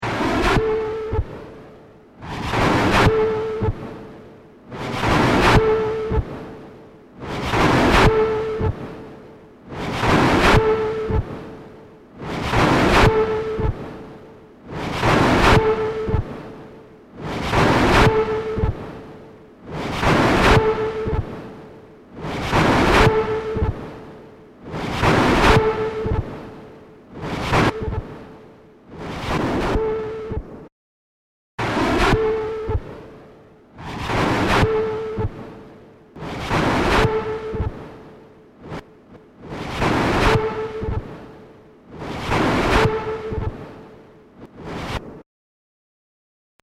These files may be downloaded and listened to as a very minimal industrial noise album, or may be downloaded for use as above.
noise loop 1 0:46 stereo 731k
noiseloop1.mp3